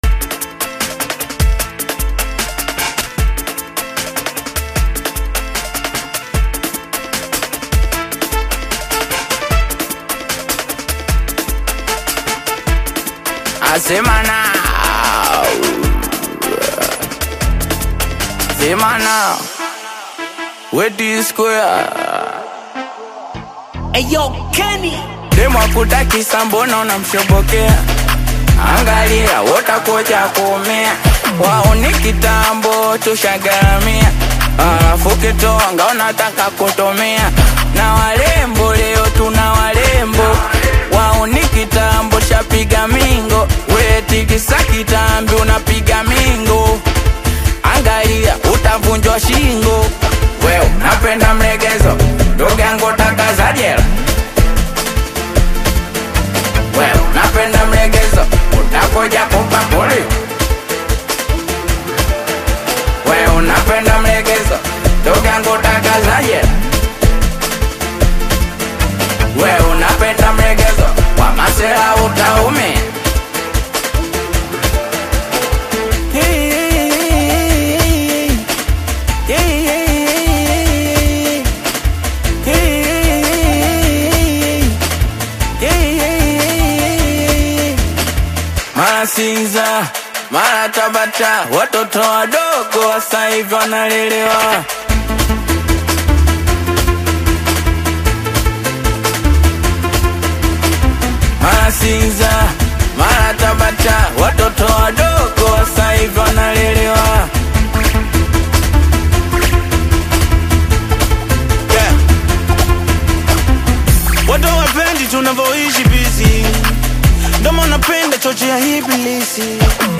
Tanzanian Singeli